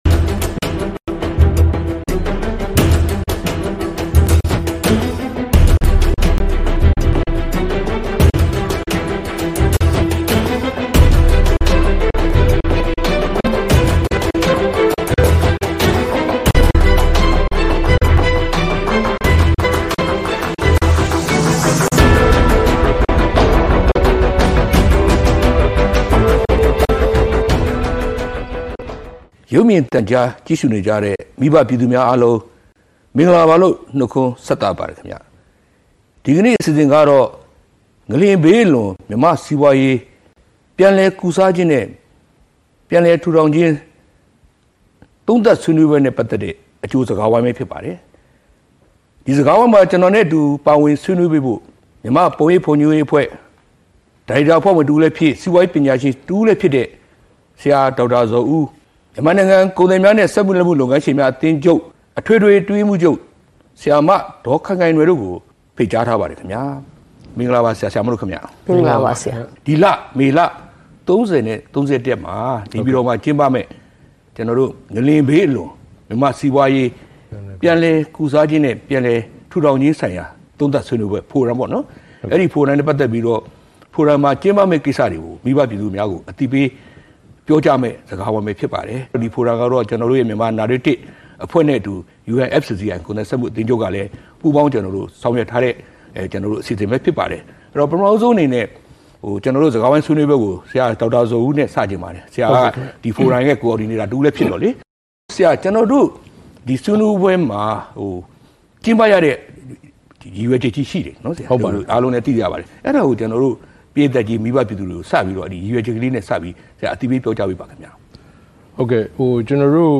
ငလျင်ဘေးအလွန် မြန်မာ့စီးပွားရေး ပြန်လည်ကုစားခြင်းနှင့် ပြန်လည်ထူထောင်ခြင်း သုံးသပ်ဆွေးနွေးပွဲ အကြိုစကားဝိုင်း (အပိုင်း-၁)